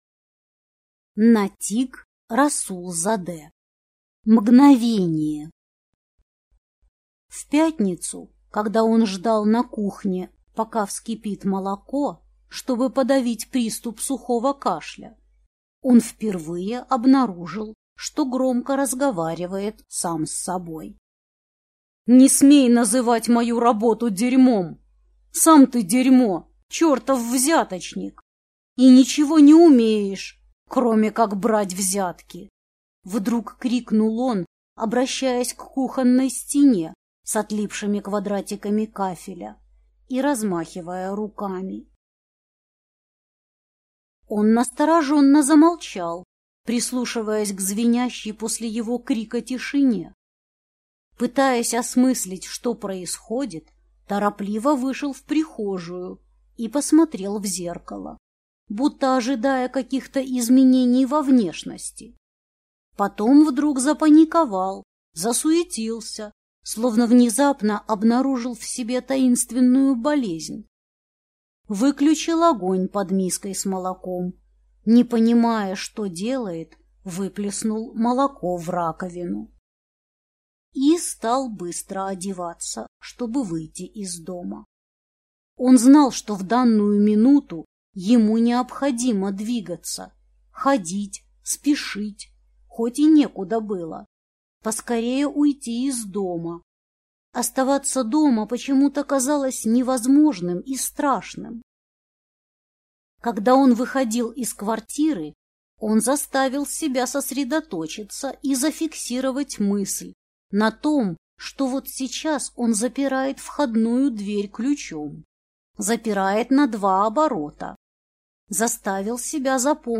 Аудиокнига Мгновение | Библиотека аудиокниг